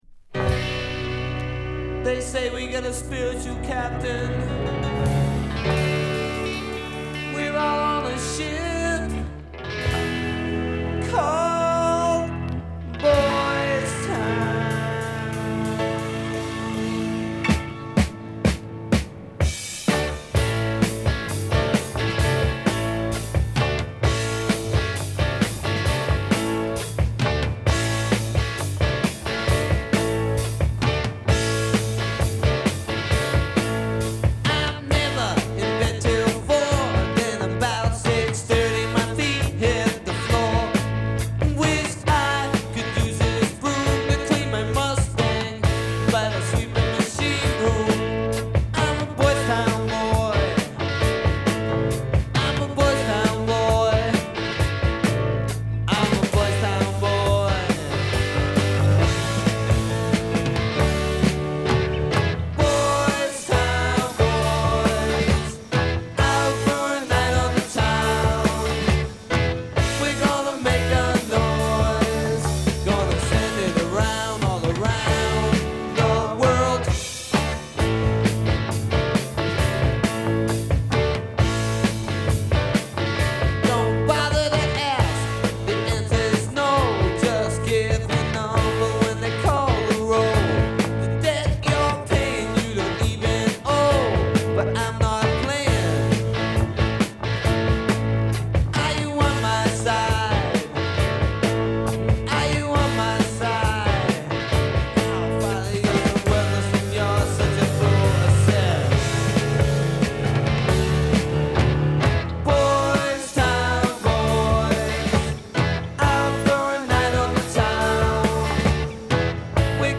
ポップ・ロック・ダブルサイダー、基本の一枚。